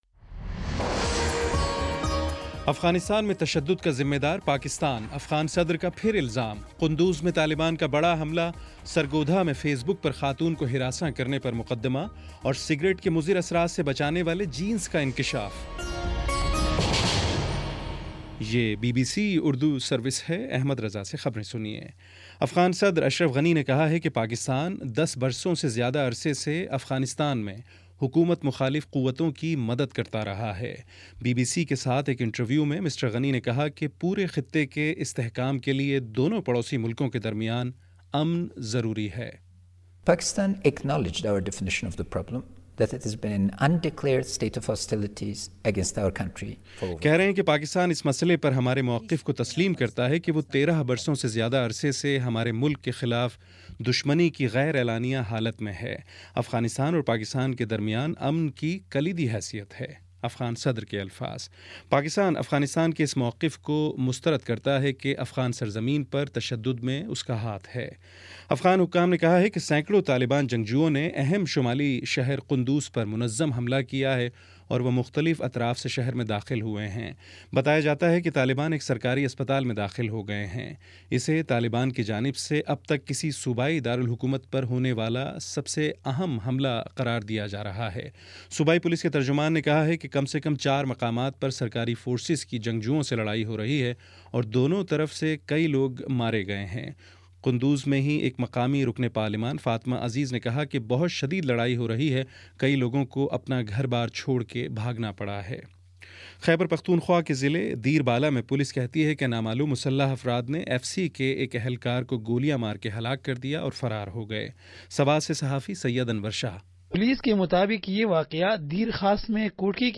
ستمبر28 : شام پانچ بجے کا نیوز بُلیٹن